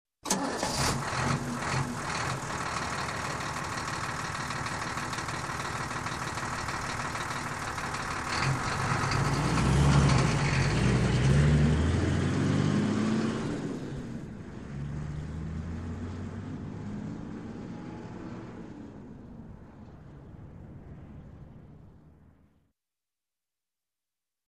Звуки грузовиков
Звук дизельного грузовика заводится двигатель и отъезжает